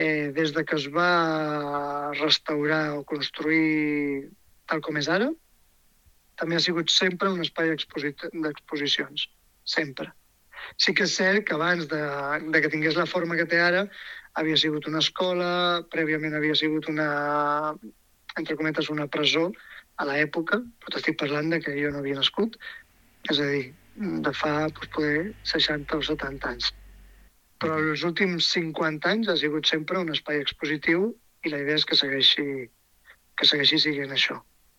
Aquest ús segueix vigent a dia d’avui, tal com explica Josep Amat, la intenció és que segueixi així.